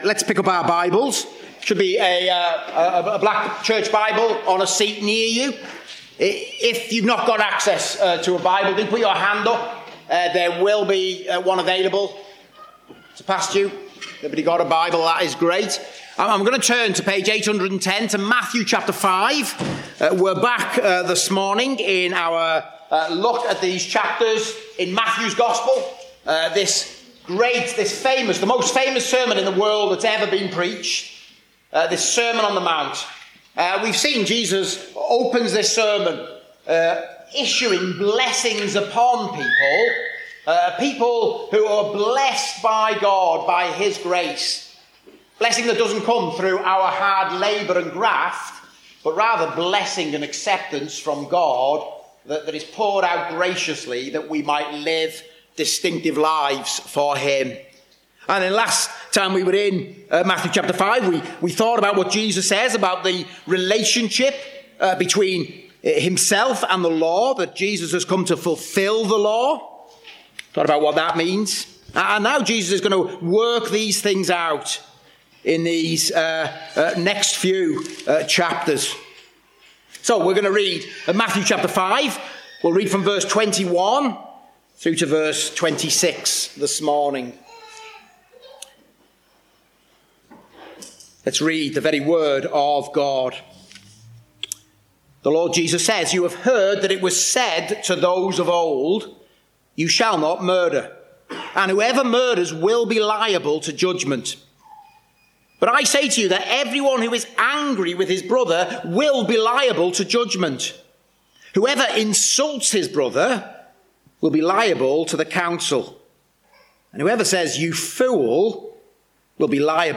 Service Type: Preaching